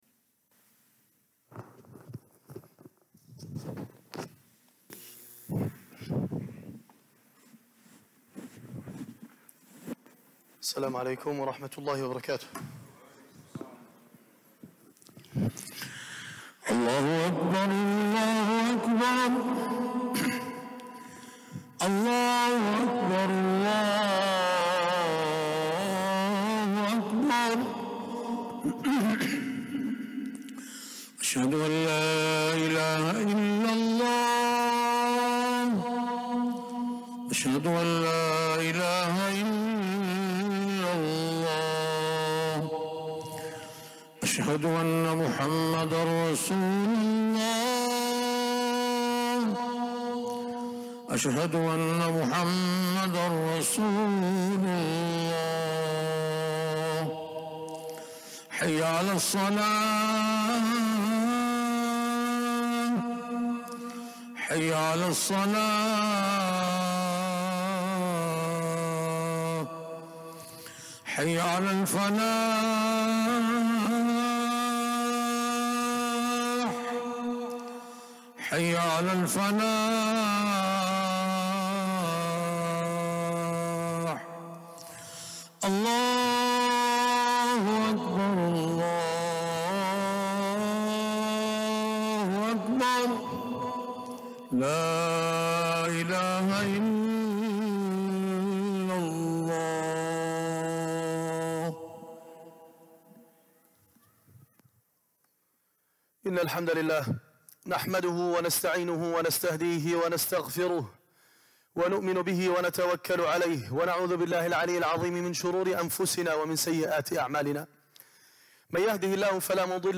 Friday Khutbah - "Fate!"